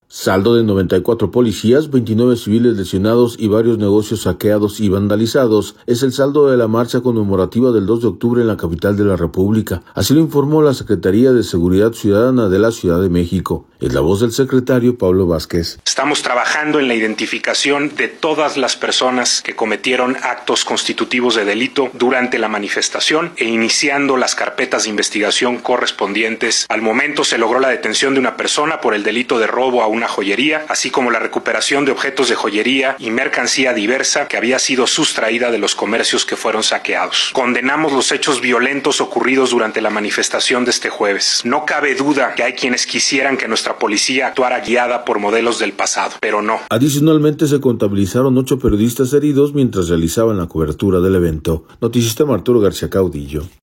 Es la voz del secretario Pablo Vázquez.